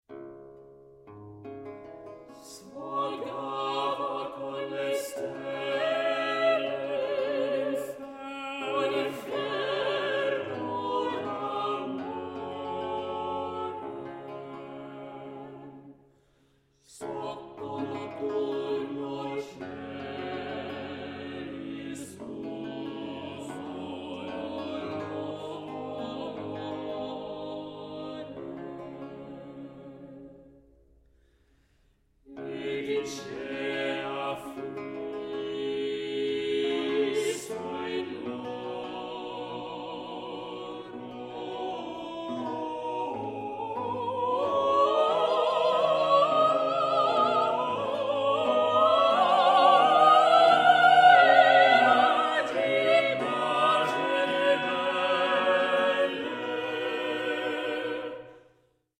soprano
mezzo-soprano
tenor
bass/baritone
chitarrone
harpsichord
countertenor